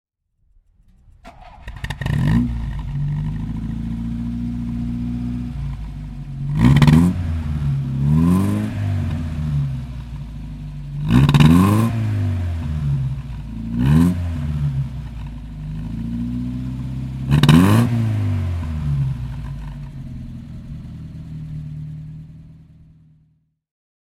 This driving report tells the story of the pretty coupé and shows it in historical and contemporary pictures, including a sound sample.
Alfa Romeo 1900 C Super Sprint (1956) - Starten und Leerlauf